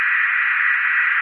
radio_random1.ogg